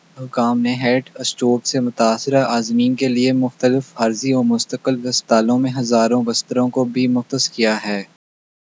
Spoofed_TTS/Speaker_02/15.wav · CSALT/deepfake_detection_dataset_urdu at main